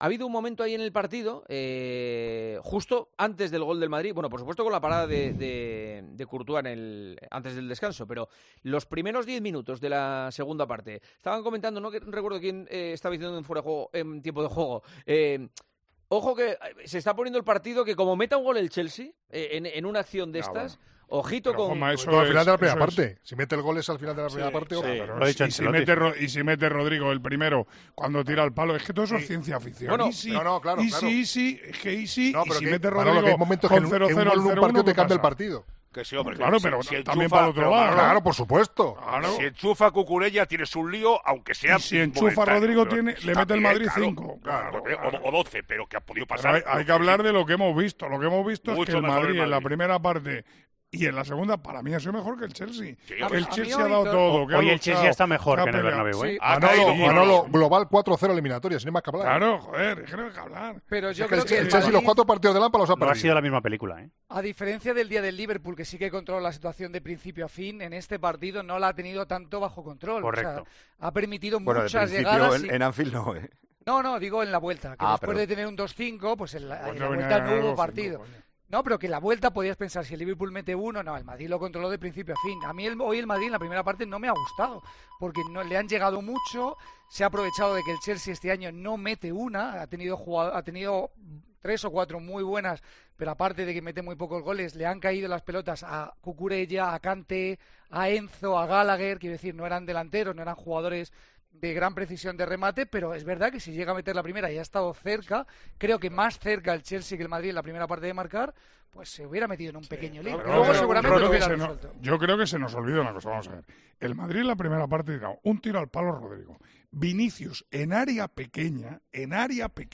Los tertulianos del Tiempo de Opinión hicieron esta reflexión tras la victoria del equipo de Carlo Ancelotti ante el Chelsea en la Champions League